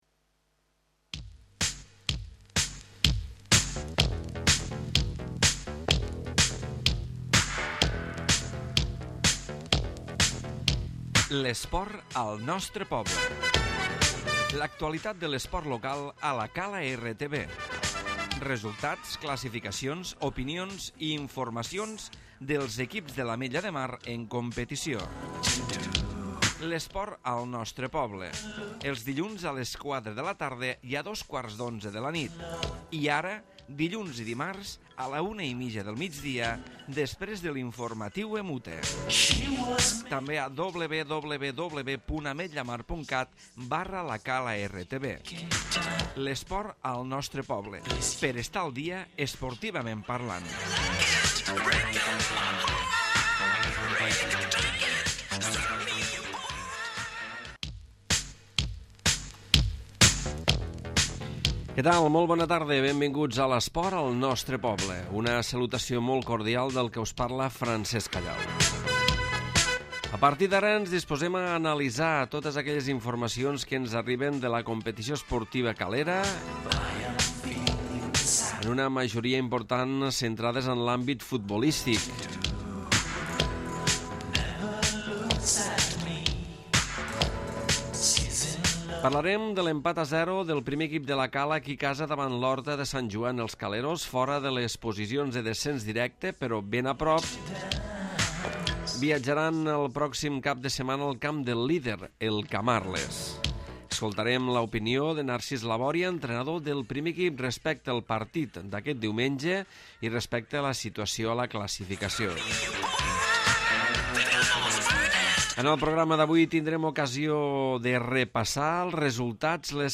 Programa esportiu on repassem l'actualitat del 1er equip de futbol de La Cala i el futbol base i la resta de l'actualitat esportiva.